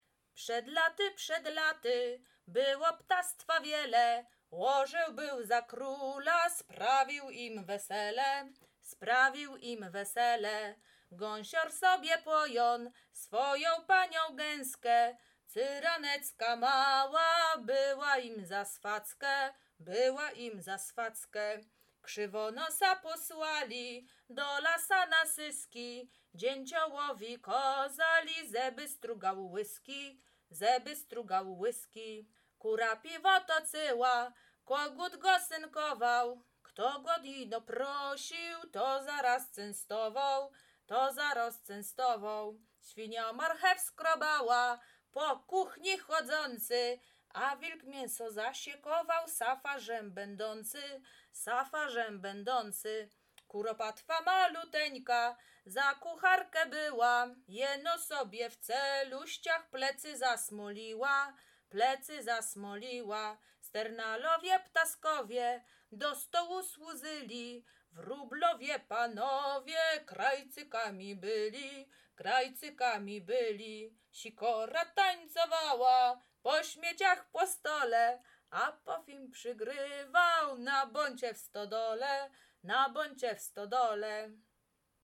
Krakowskie
Kolęda